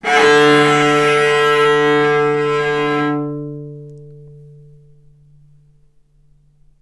vc_sp-D3-ff.AIF